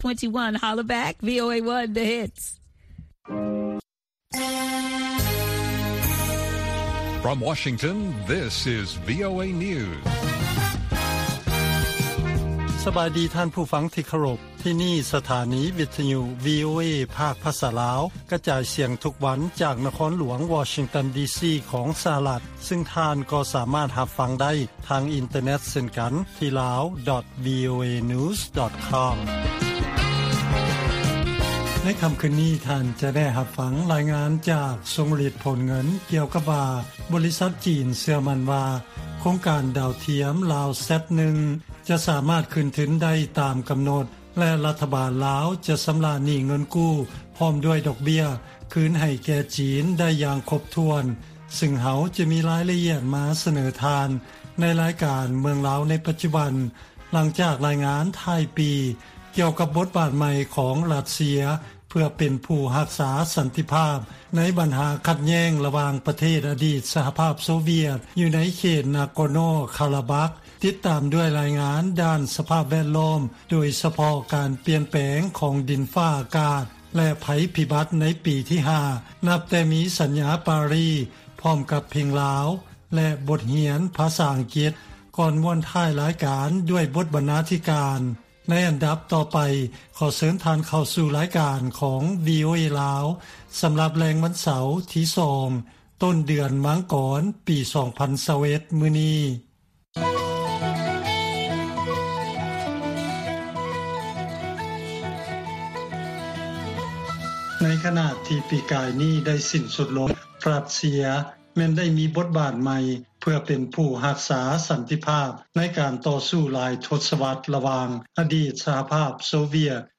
ລາຍການກະຈາຍສຽງຂອງວີໂອເອ ລາວ
ວີໂອເອພາກພາສາລາວ ກະຈາຍສຽງທຸກໆວັນ. ຫົວຂໍ້ຂ່າວສໍາຄັນໃນມື້ນີ້ມີ: 1) ດ້ວຍພາລະກິດ ຜູ້ພິທັກສັນຕິພາບ, ຣັດເຊຍ ຄົ້ນພົບສະຖານະພາບໃໝ່ ໃນພາກພື້ນ ນາກໍໂນ ຄາຣາບັກ.